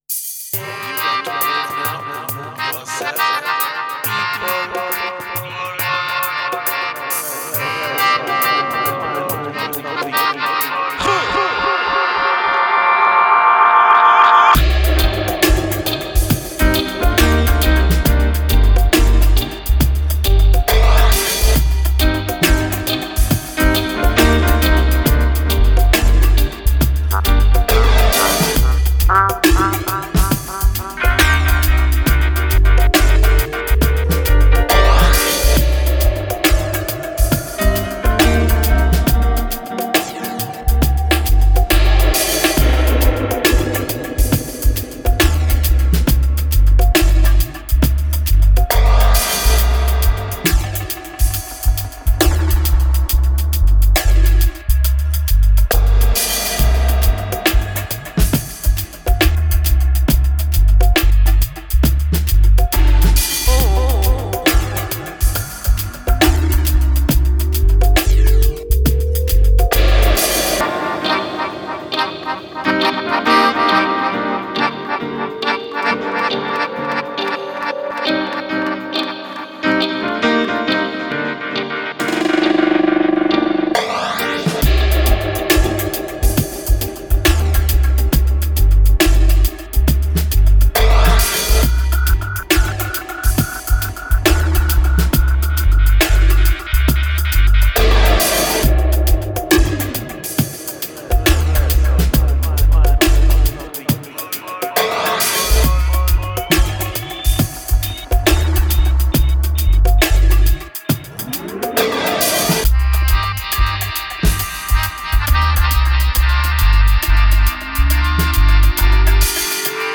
Genre: Reggae, Dub.